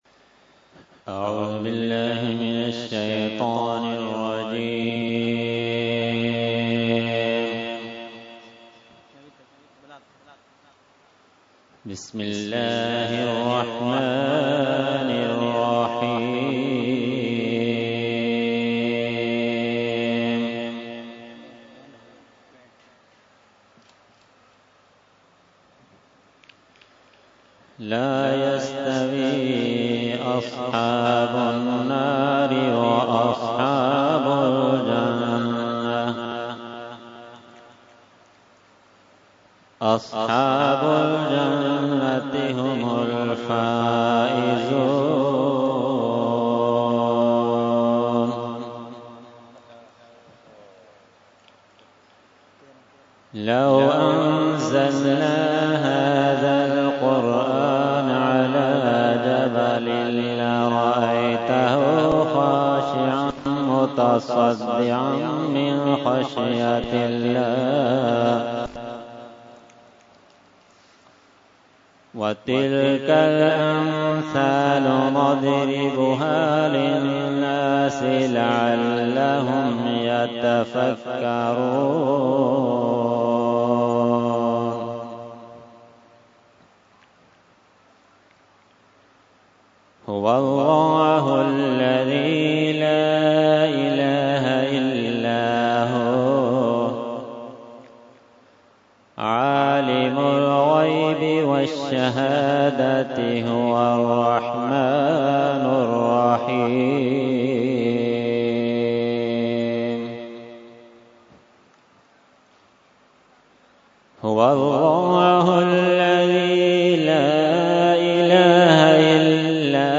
Qirat – Urs Ashraful Mashaikh 2018 – Dargah Alia Ashrafia Karachi Pakistan